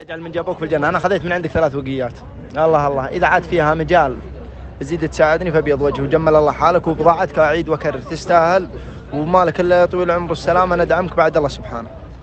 التوصيات الصوتية من عملائنا
توصية صوتية 4